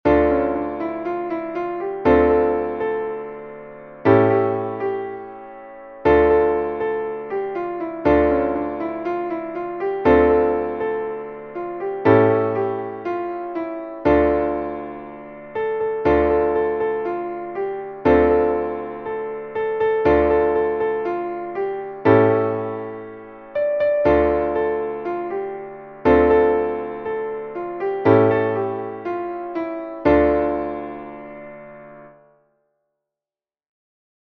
Traditional / Spiritual / Gospel